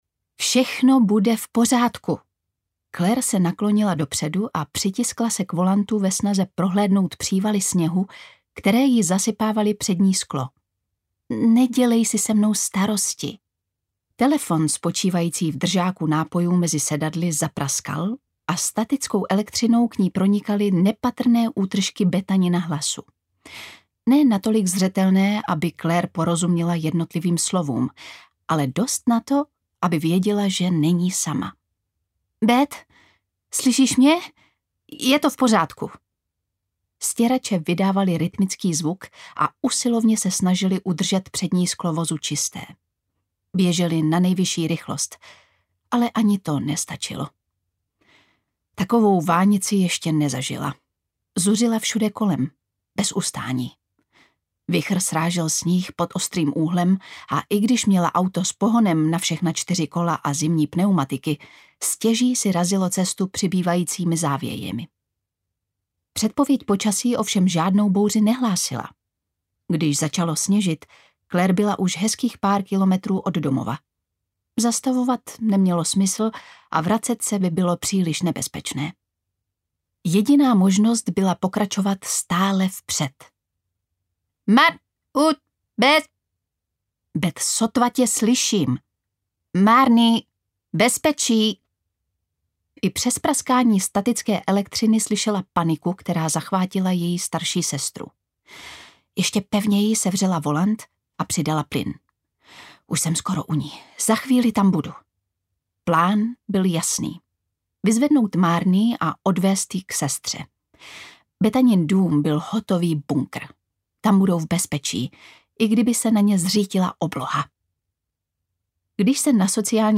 Hlasy v bouři audiokniha
Ukázka z knihy
• InterpretLucie Vondráčková